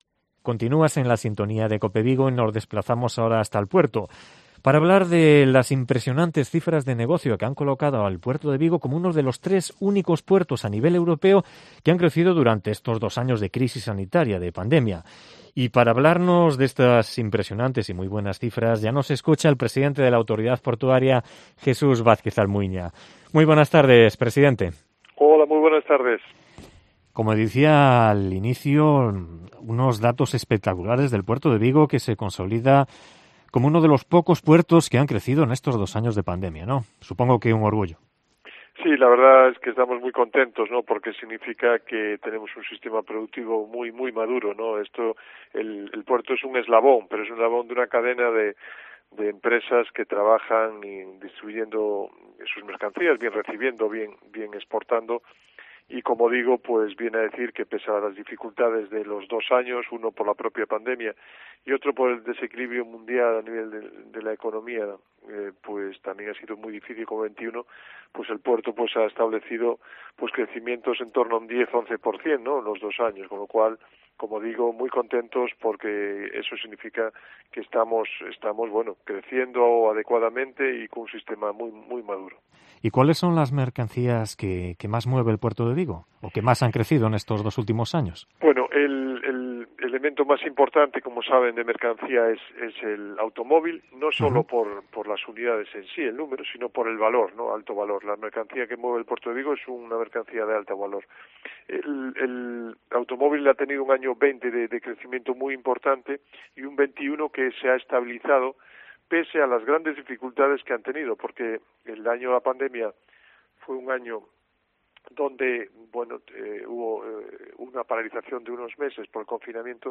Entrevista a Jesús Vázquez Almuiña, presidente de la Autoridad Portuaria de Vigo